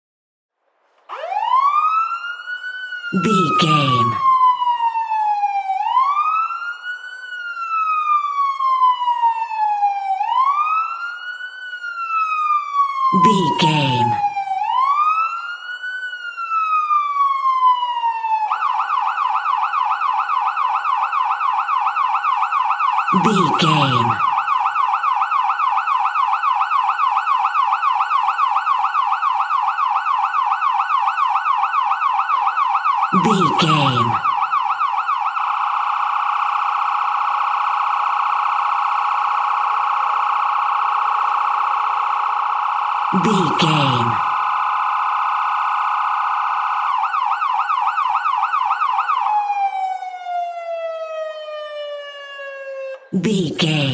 Ambulance Ext Large Short Stress Siren Distant
Sound Effects
urban
chaotic
emergency